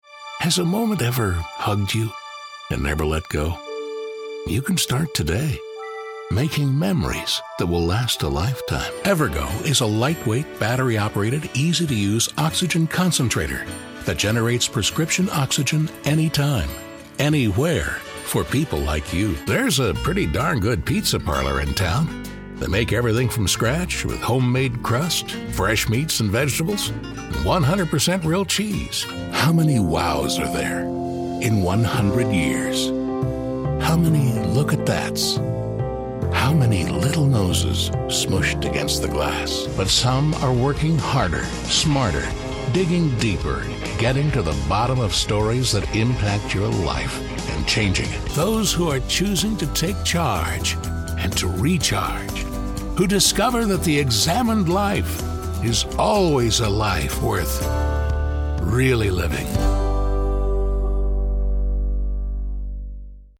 He has a rich, warm voice, a natural style, and can also be very funny.
middle west
Sprechprobe: Werbung (Muttersprache):